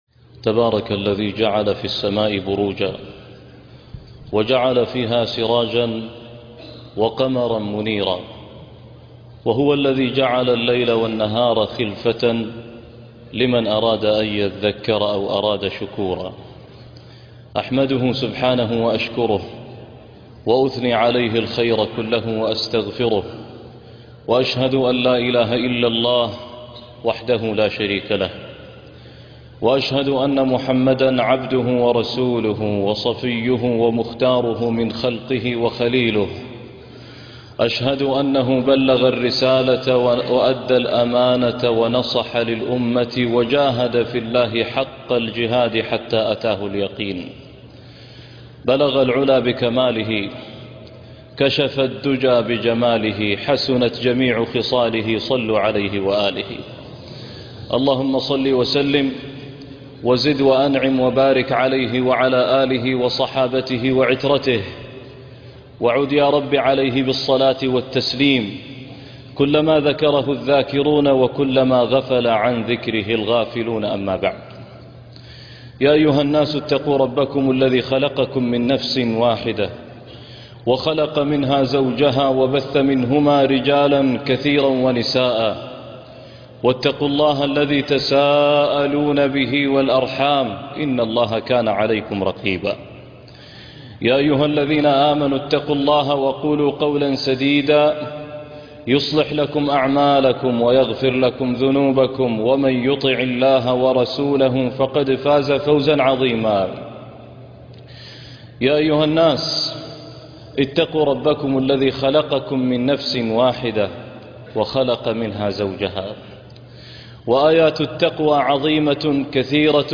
إنا لله وإنا إليه راجعون - خطبة الجمعة